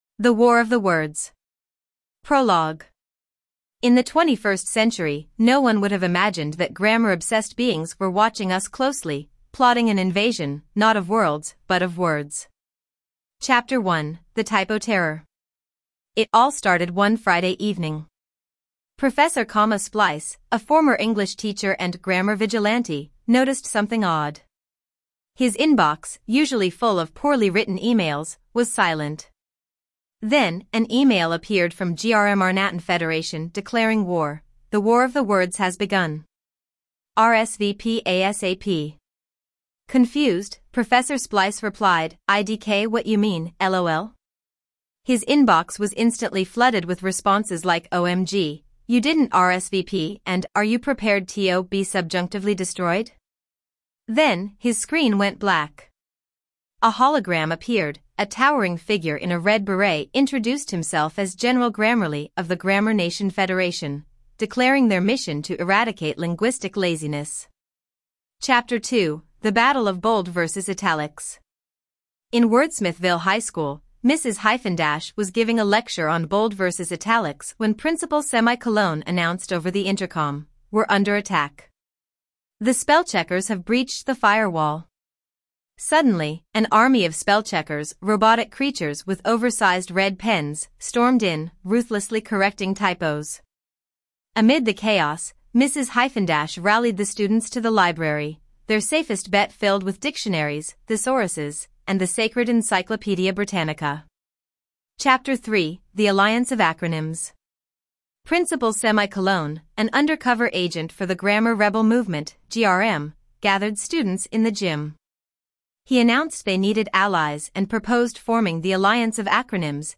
Teacher reading his email
More Audio Books